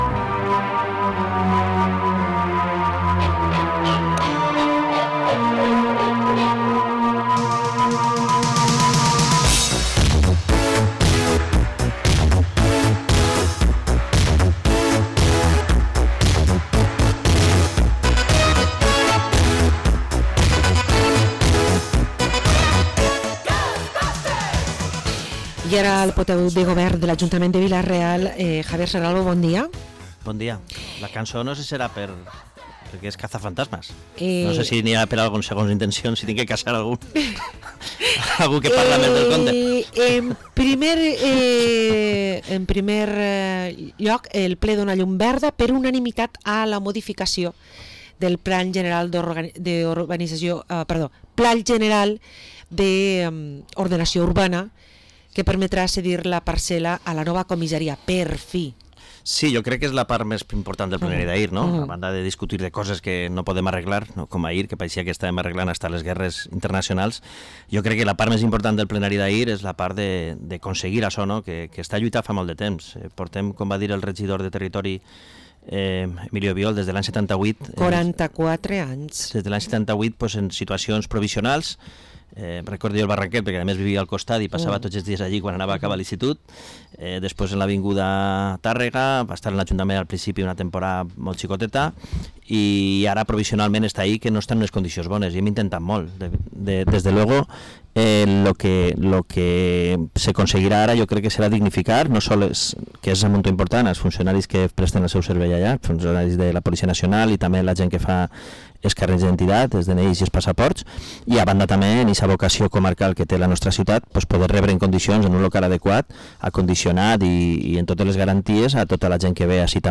Entrevista al portavoz del equipo de gobierno de Vila-real, Javier Serralvo